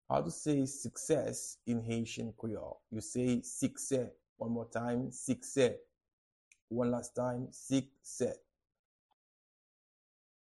Pronunciation and Transcript:
How-to-say-Success-in-Haitian-Creole-Sikse-pronunciation.mp3